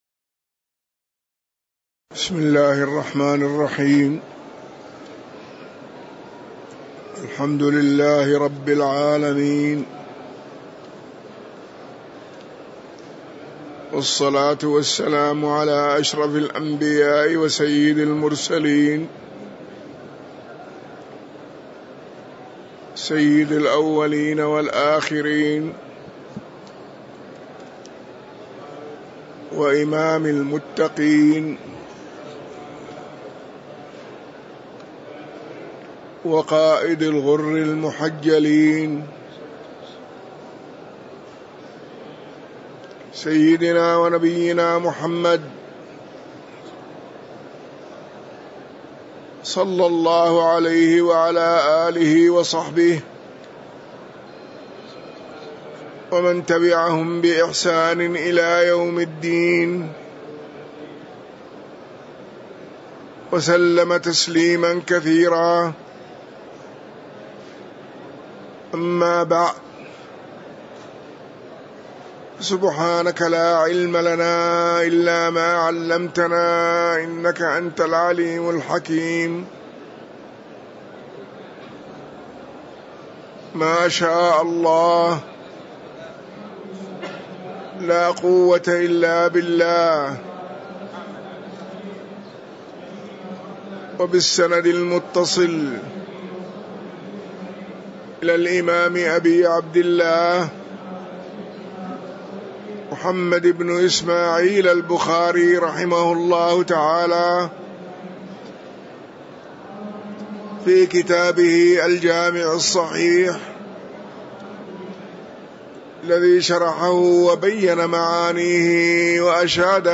تاريخ النشر ٩ ربيع الأول ١٤٤٤ هـ المكان: المسجد النبوي الشيخ